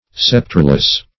Sceptreless \Scep"tre*less\